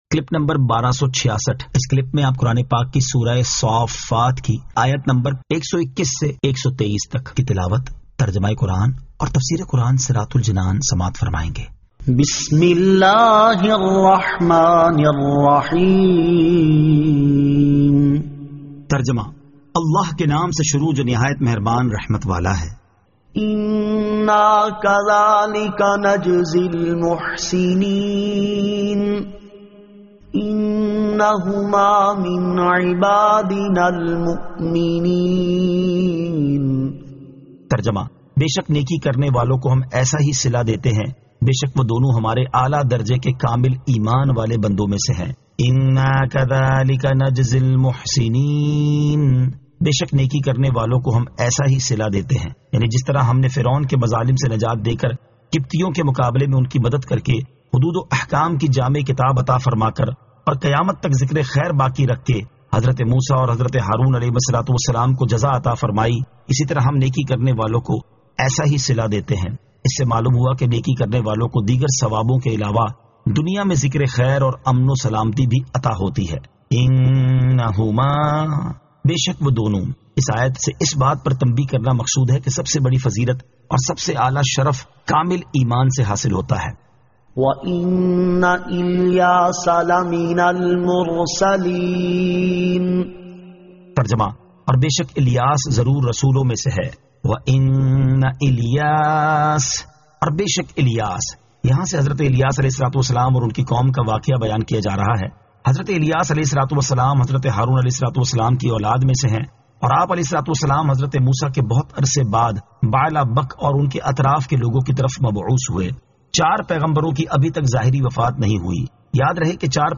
Surah As-Saaffat 121 To 123 Tilawat , Tarjama , Tafseer